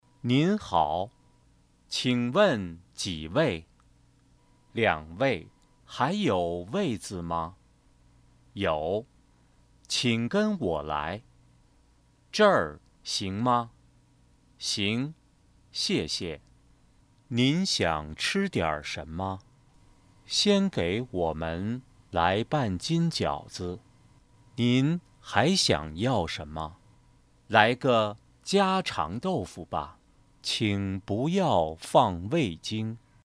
Best Voice in Chinese (Mandarin) Warm, Bright, Deep, Smooth and Professional.
Sprechprobe: Sonstiges (Muttersprache):